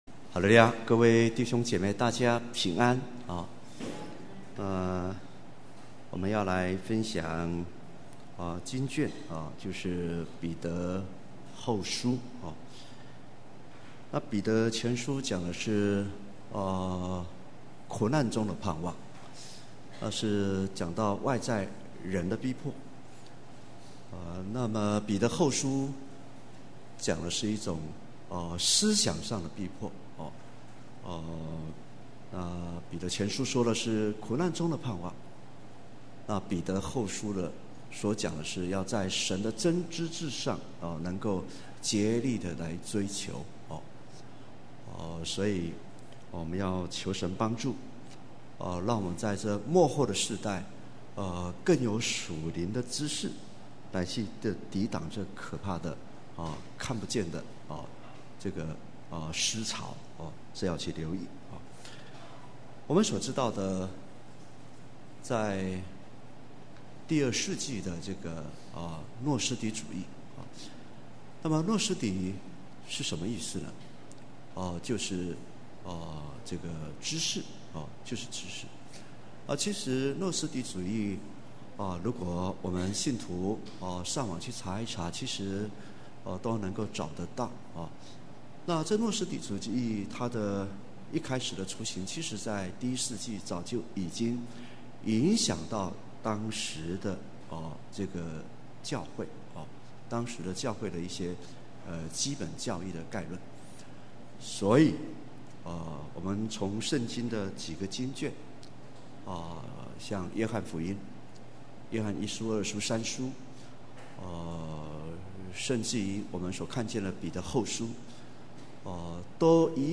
2018年12月份講道錄音已全部上線